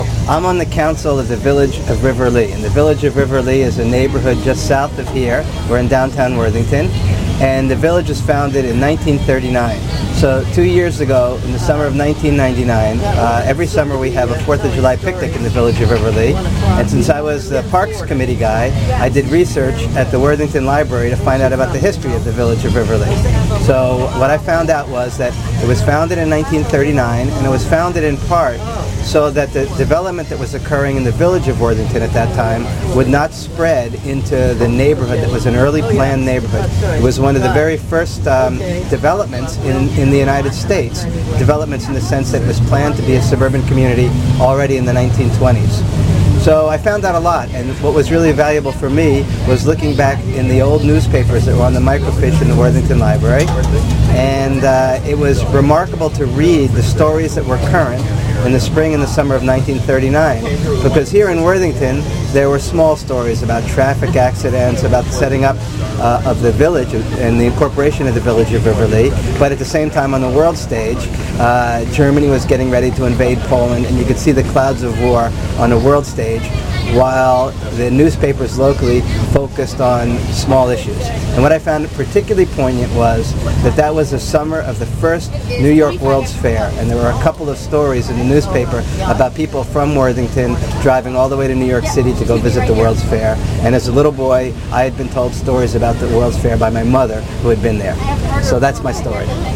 In July 2001 Worthington Libraries invited the community to share their earliest memories and fondest recollections of life in Worthington at the Worthington FolkFEST.